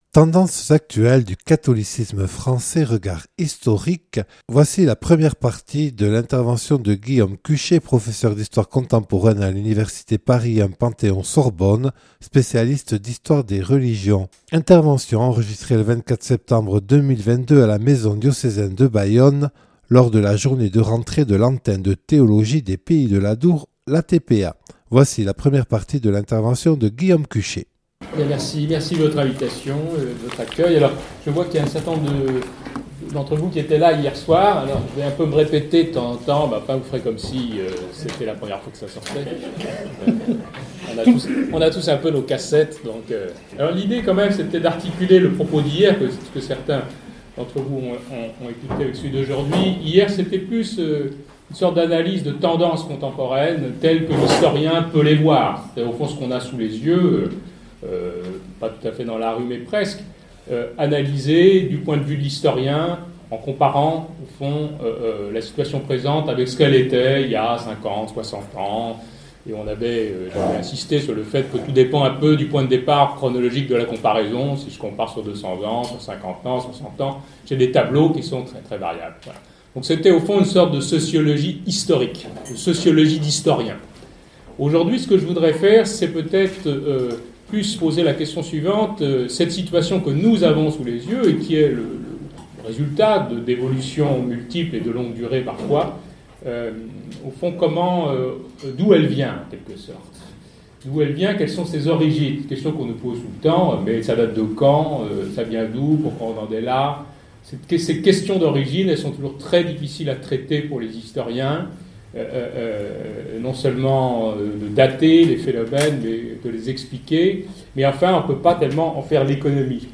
(Enregistrée le 24/09/2022 à la Maison diocésaine lors de la journée de rentrée de l’Antenne de Théologie des Pays de l’Adour).